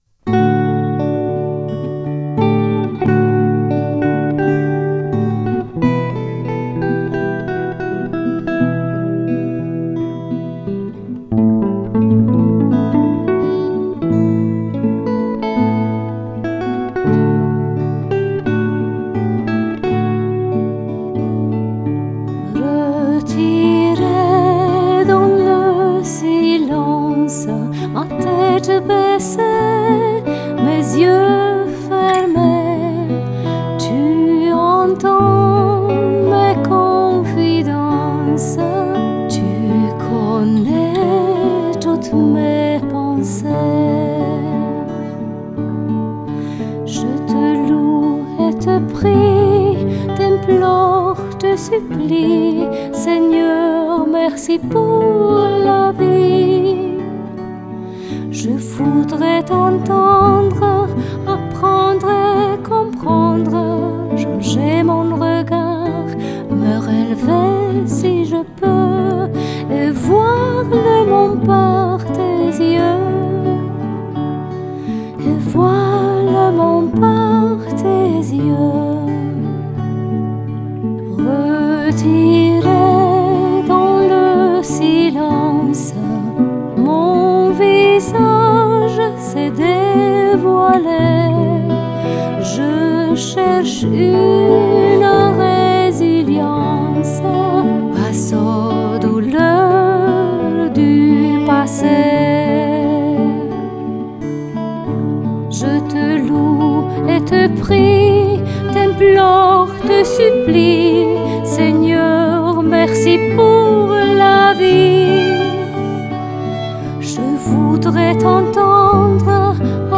Chant
Guitares et percussions
Violon